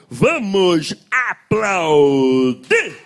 Apresentador Raul Gil pede: vamos aplaudir!